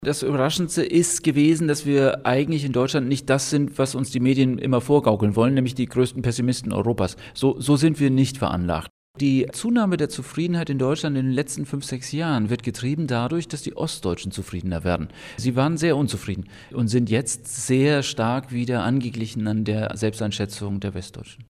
O-Töne / Radiobeiträge, , , ,